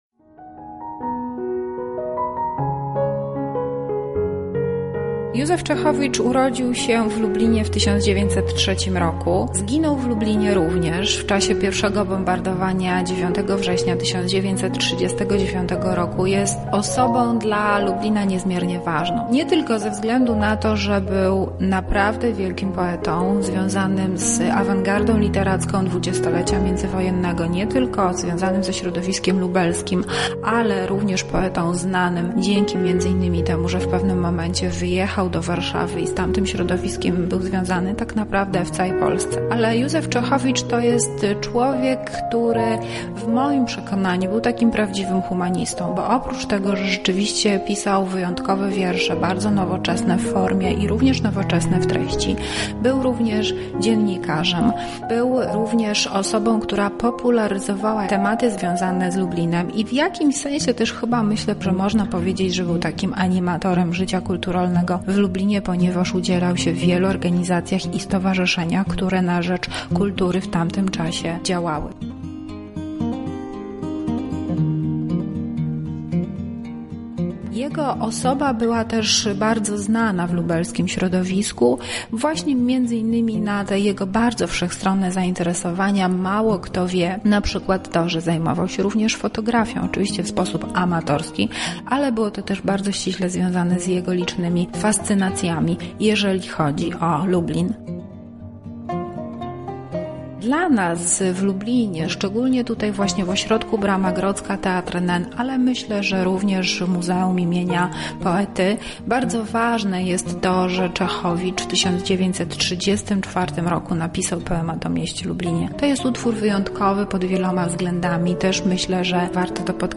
Na ten temat porozmawiała nasza reporterka